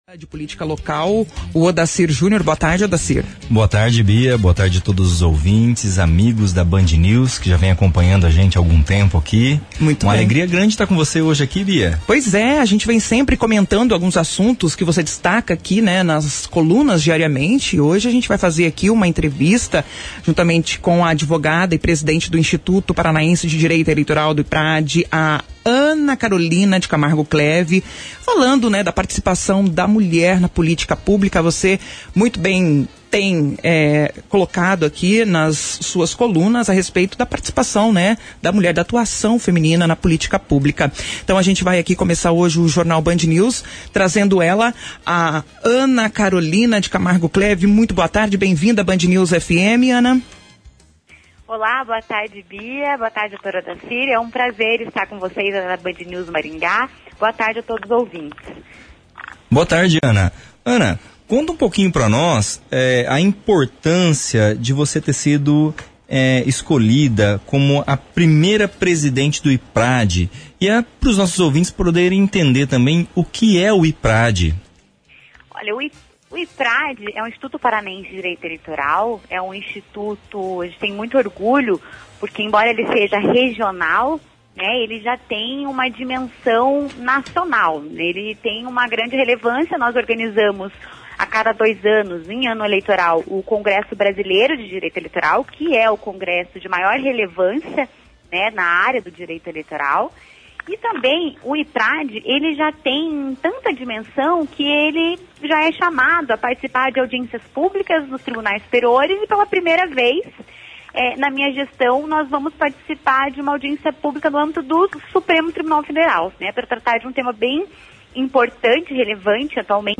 concedeu entrevista, no dia 12 de novembro, à BandNews FM Maringá para abordar a participação feminina na política, visando as eleições municipais do ano que vem.